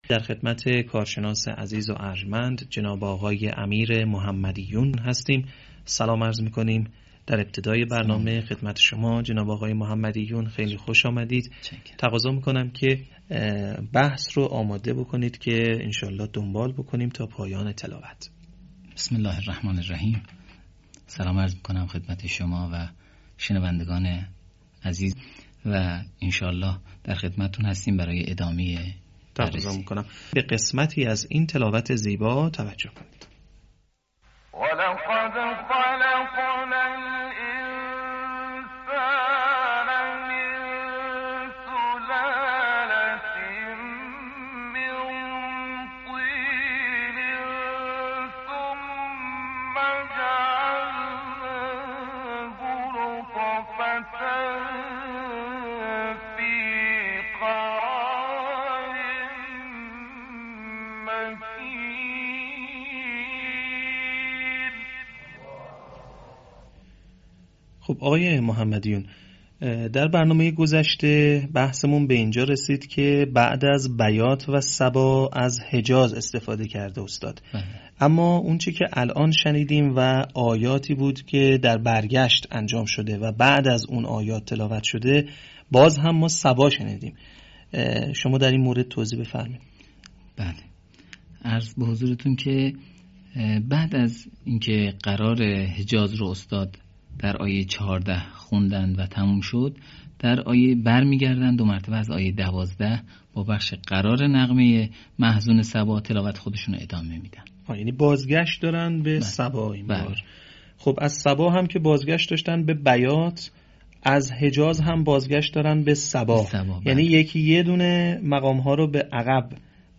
صوت | تحلیل تلاوت «عبدالحکم» در مقام بیات و صبا